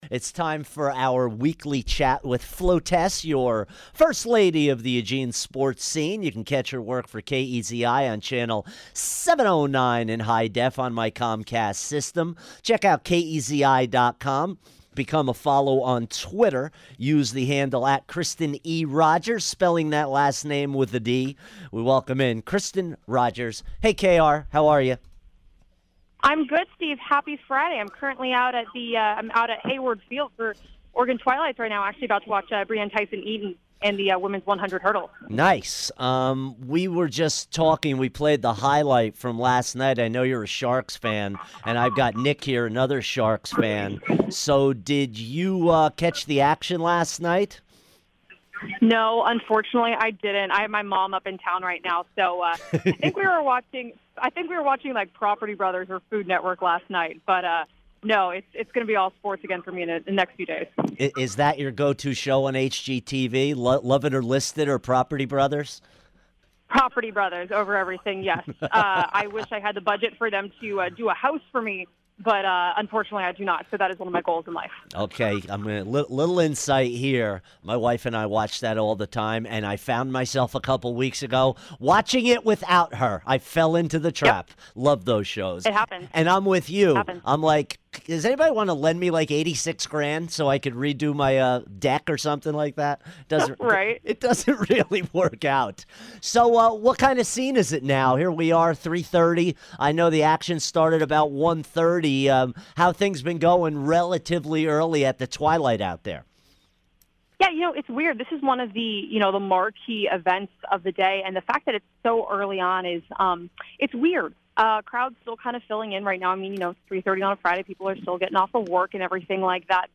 Interview
calls in from Hayward Field